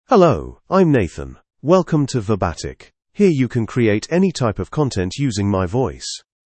MaleEnglish (United Kingdom)
Nathan is a male AI voice for English (United Kingdom).
Voice sample
Nathan delivers clear pronunciation with authentic United Kingdom English intonation, making your content sound professionally produced.